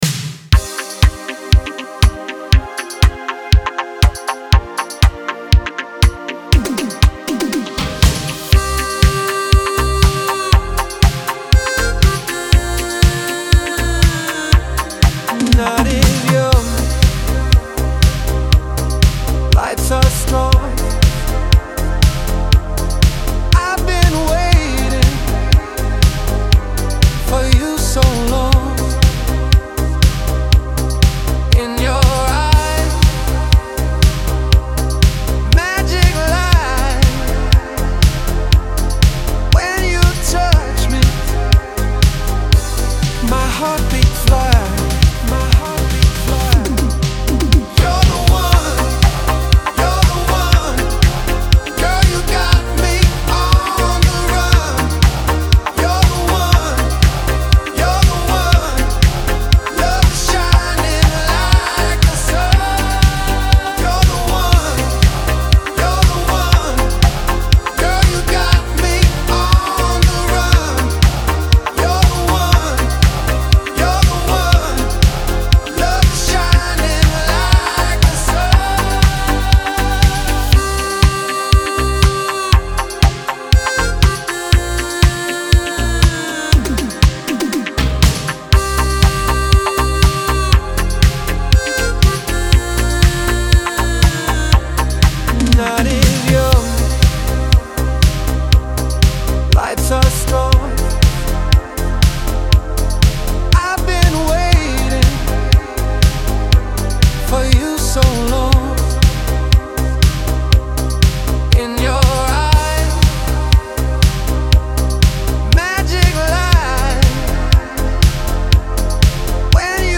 эстрада
pop
диско
dance